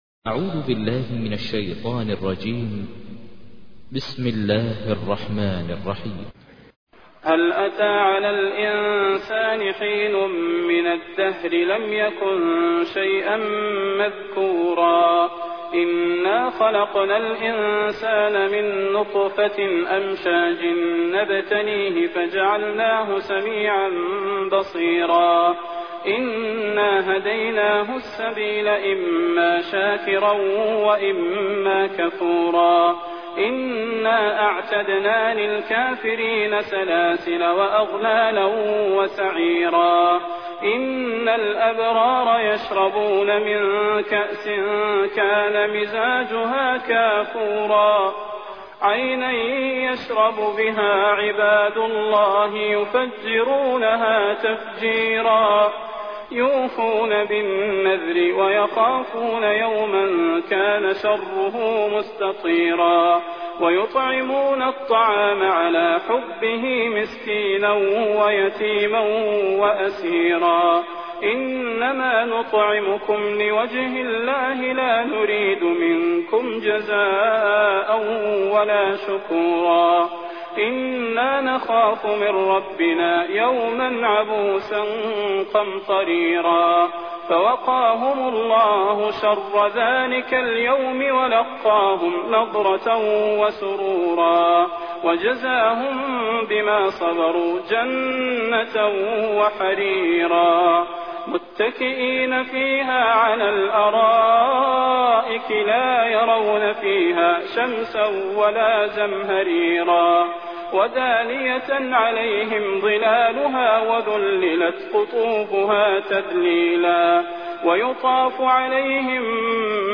تحميل : 76. سورة الإنسان / القارئ ماهر المعيقلي / القرآن الكريم / موقع يا حسين